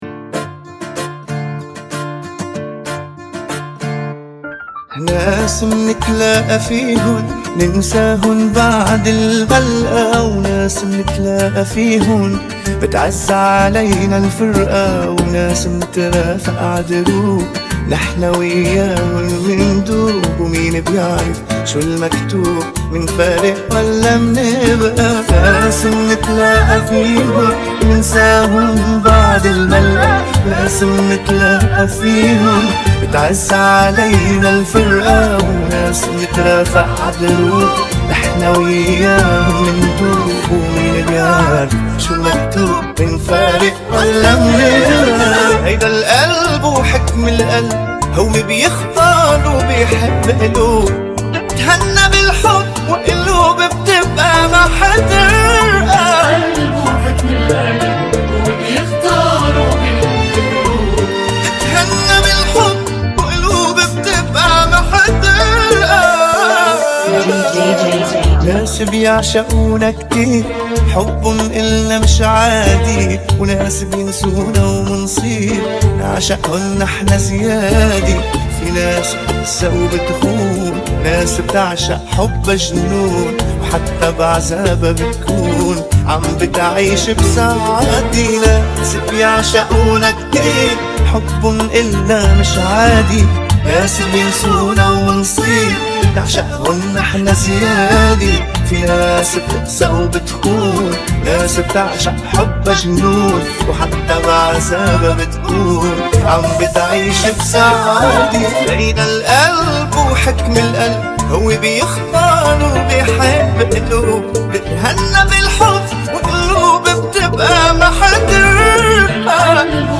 95 BPM
Genre: Bachata Remix